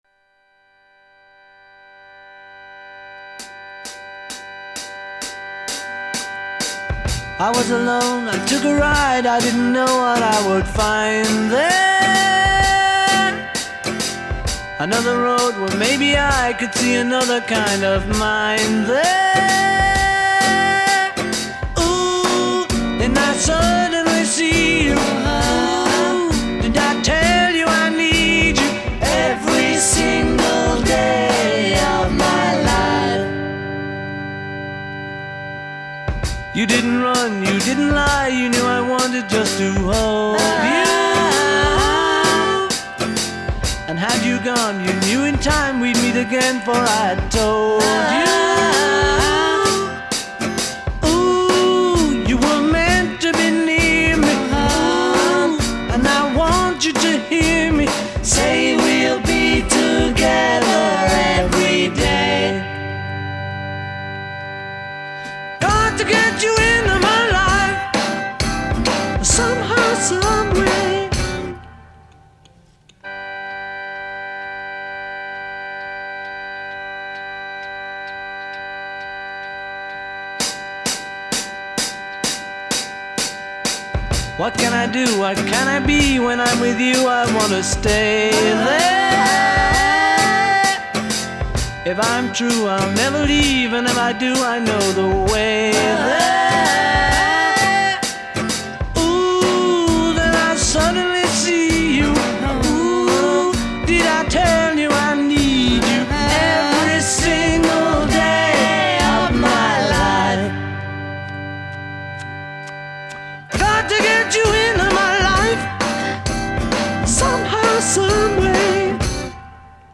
Рок музыка